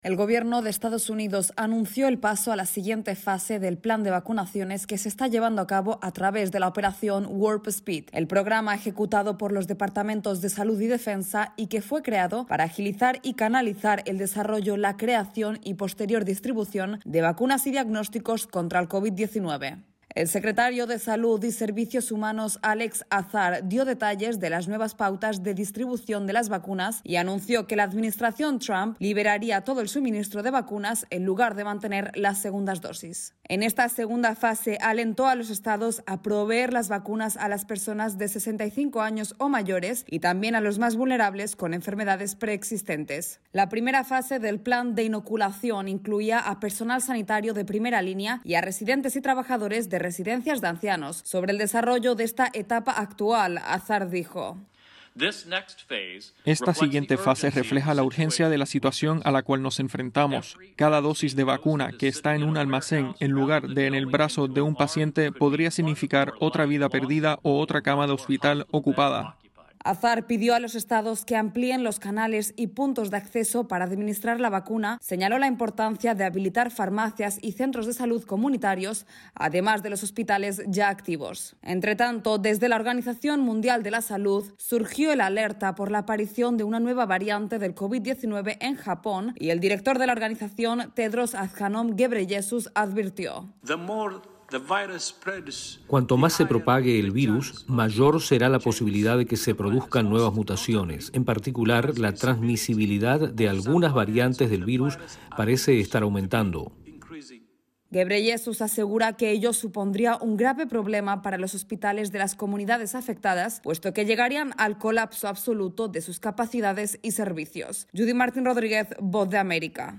Noticiero 13.01.2021